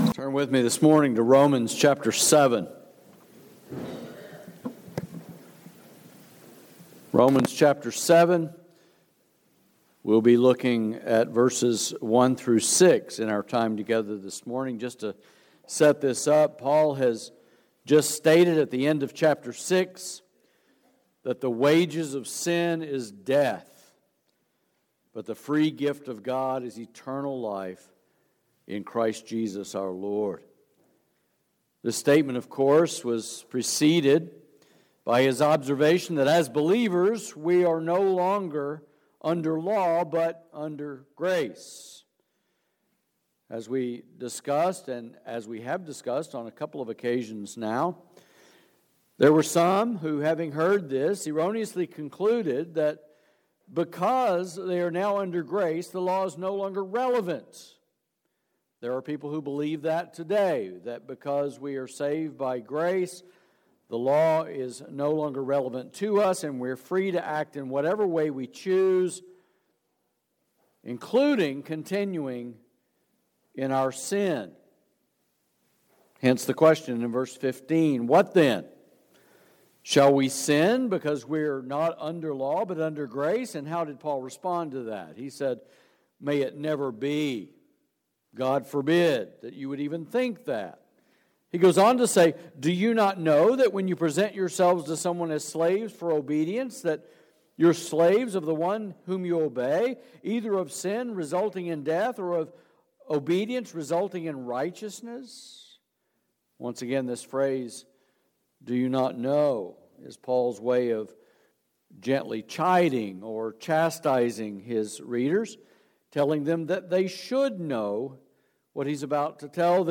Sermons | Grace Baptist Church San Antonio